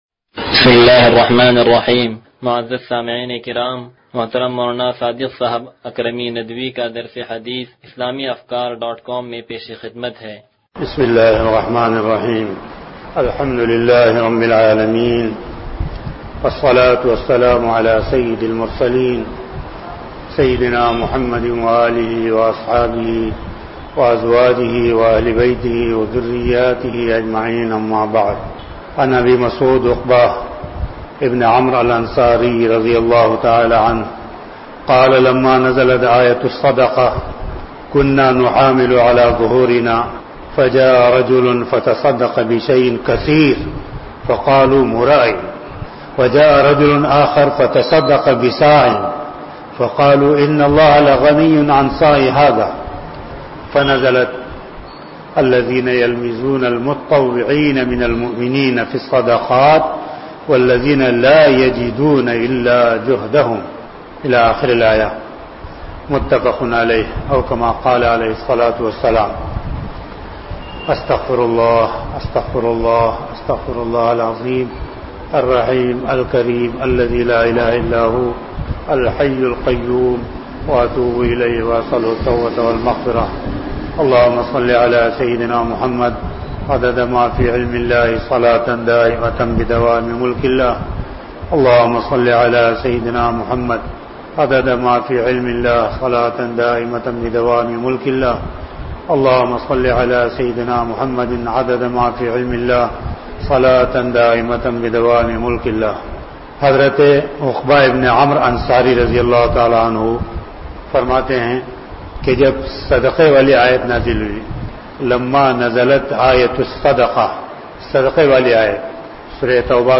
درس حدیث نمبر 0118
سلطانی مسجد